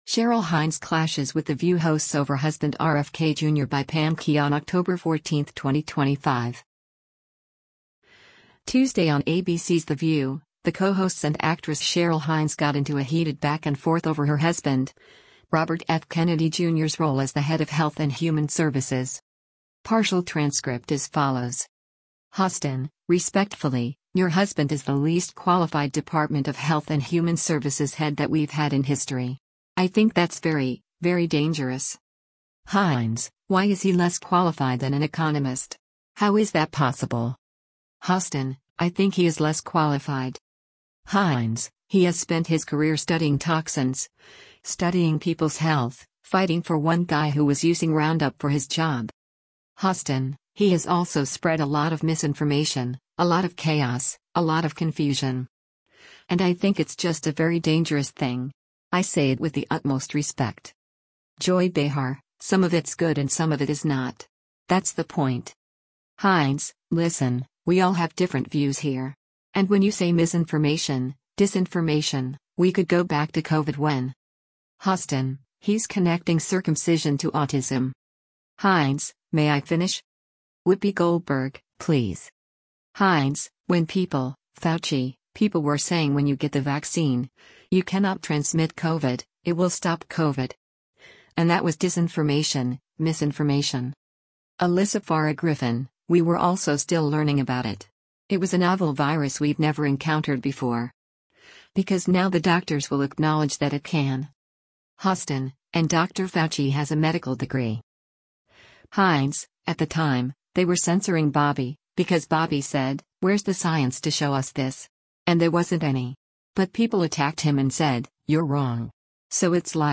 Tuesday on ABC’s “The View,” the co-hosts and actress Cheryl Hines got into a heated back-and-forth over her husband, Robert F. Kennedy Jr.’s role as the head of Health and Human Services.